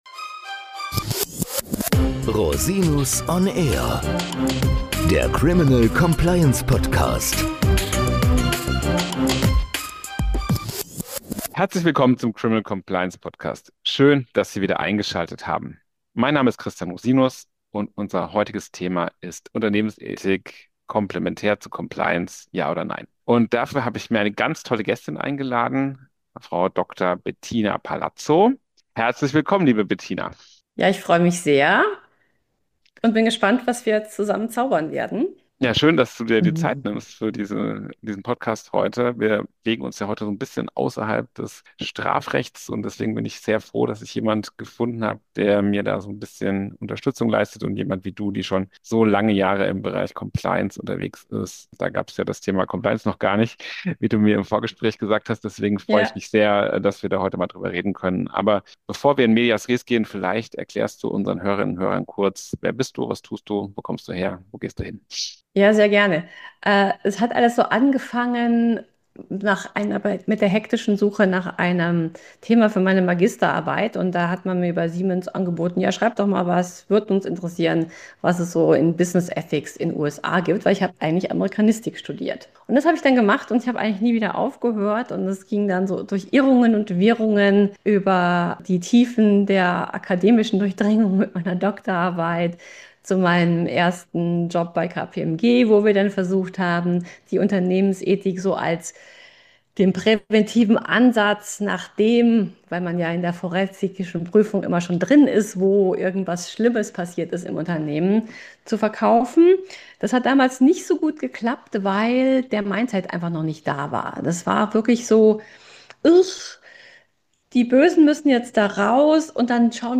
Ethische Grauzonen und psychologische Ursachen von Fehlverhalten - Interview